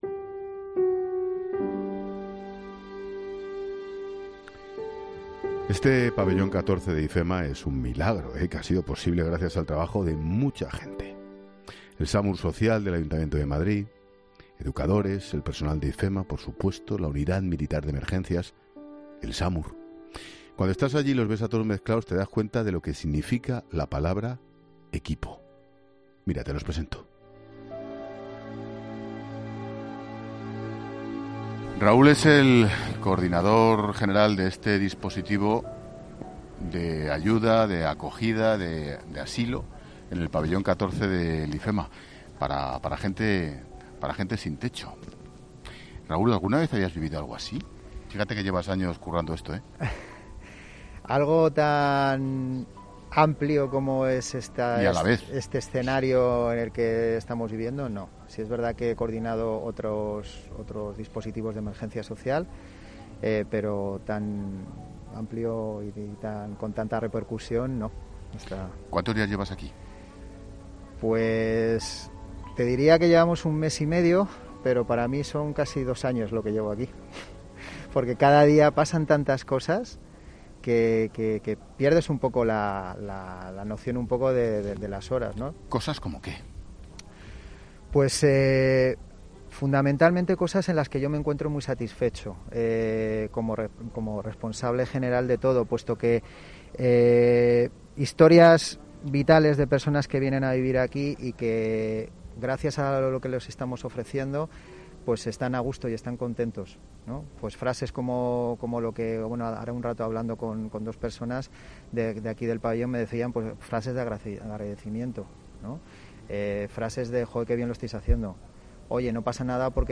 Hoy hemos vuelto a salir a la calle para conocer cómo se han enfrentado los sin techo a esta crisis sanitaria.
Acompános porque hoy en nuestro tema del día te llevo a un lugar que es digno de ver. 00:00 Volumen Cerrar Así se montó el Pabellón 14 de los sin techo en IFEMA - Este pabellón 14 de IFEMA es un milagro.